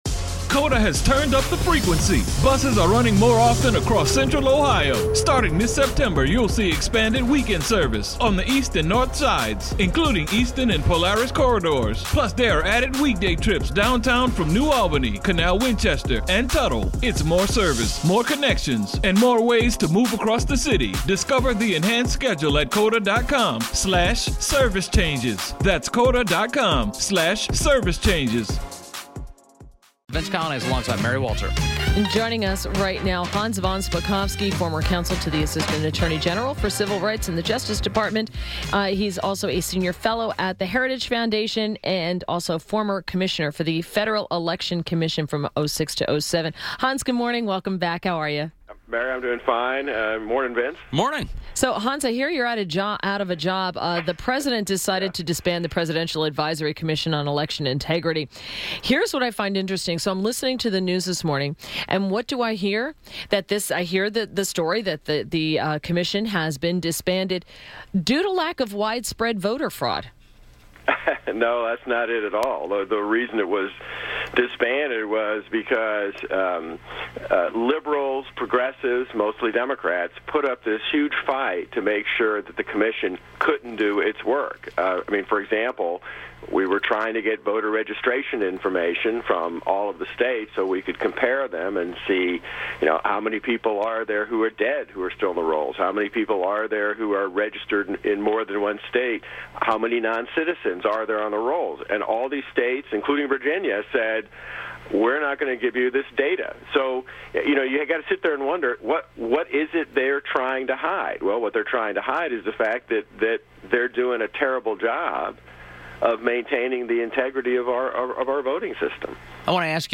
WMAL Interview - HANS VON SPAKOVSKY - 01.04.18